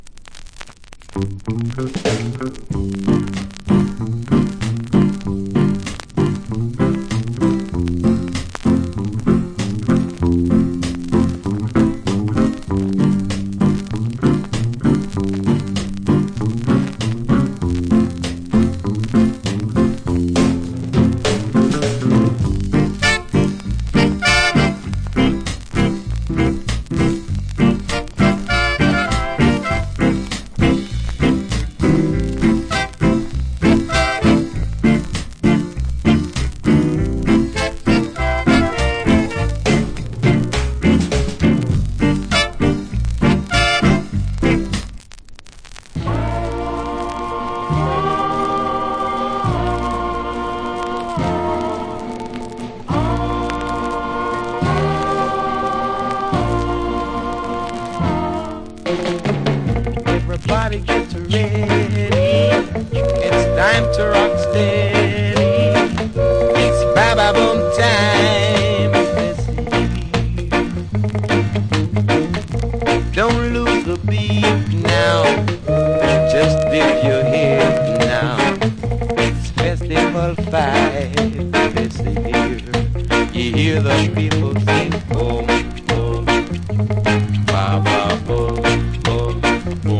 Great Rock Steady Inst.